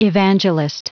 Prononciation du mot evangelist en anglais (fichier audio)
Prononciation du mot : evangelist